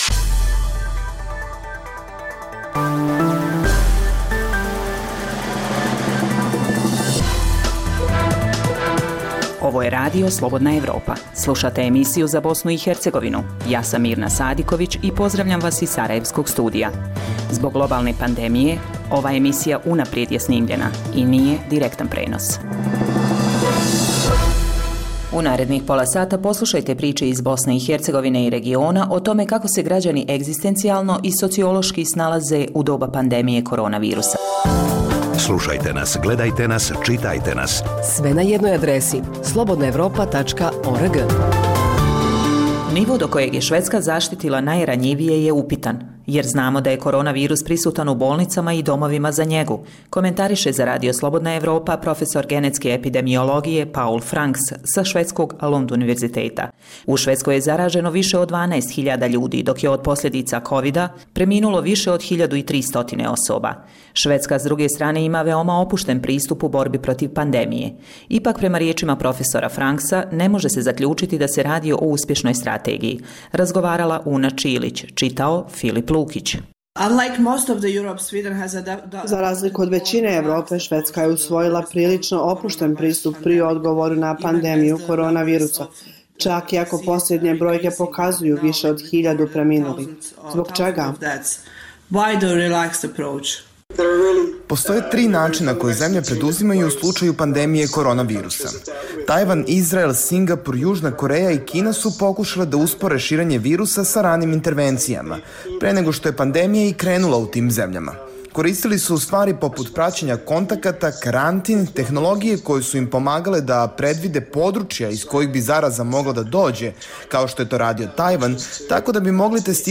Sadrži lokalne, regionalne i vijesti iz svijeta, tematske priloge o aktuelnim dešavanjima. Zbog pooštrenih mjera kretanja u cilju sprečavanja zaraze korona virusom, ovaj program je unaprijed snimljen.